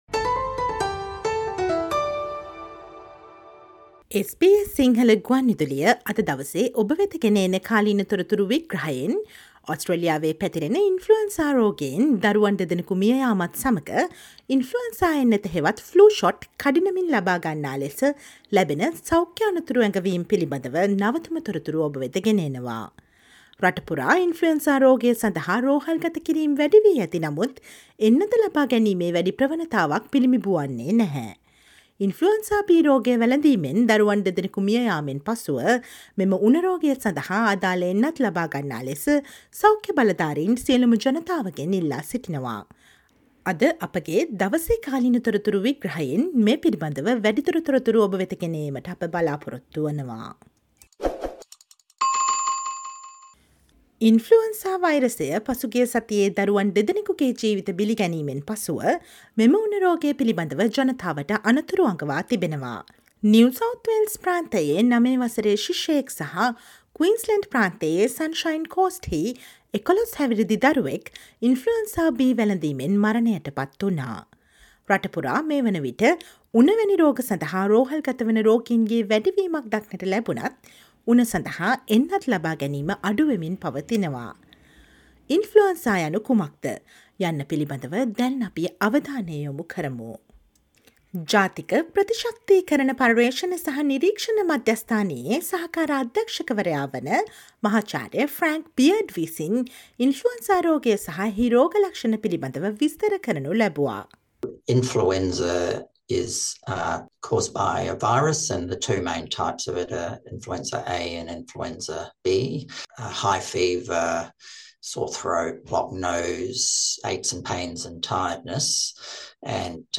Listen to the SBS Sinhala explainer on what is Influenza B and why it is importance to get vaccinated against it.